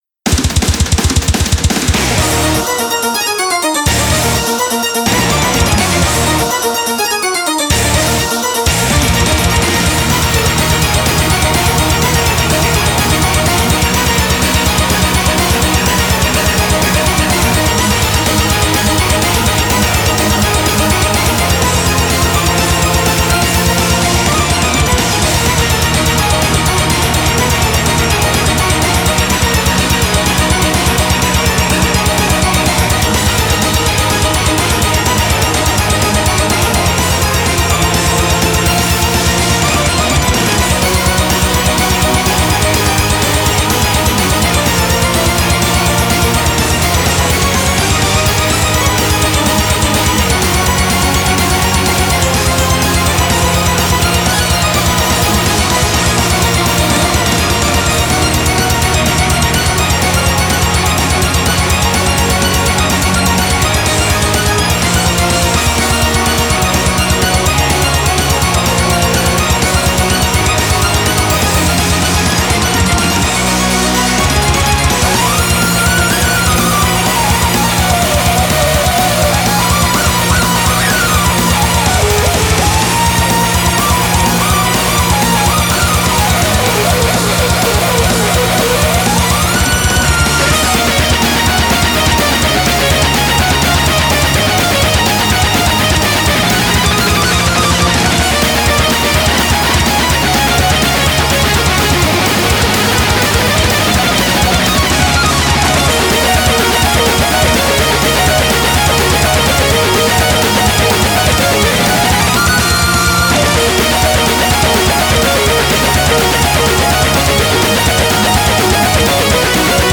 BPM250
Audio QualityPerfect (High Quality)
Anyways, really fast song at 250BPM.